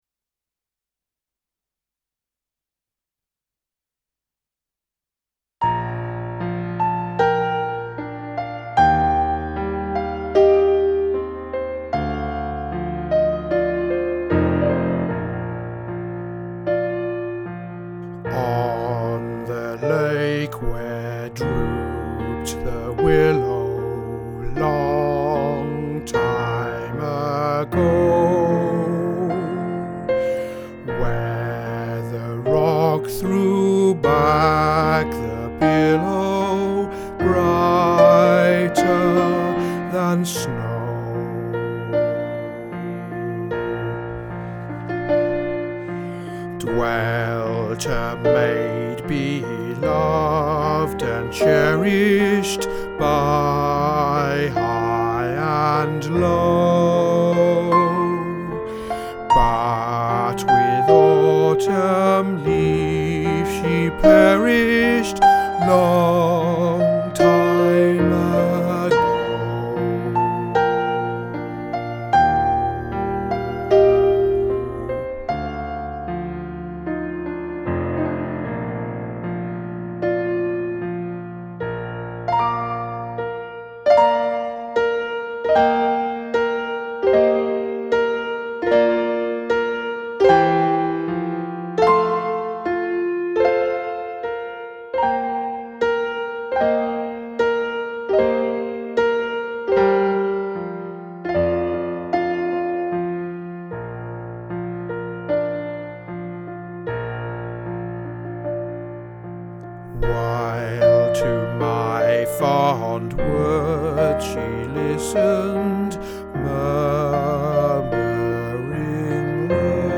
Long-Time-Ago-Bass.mp3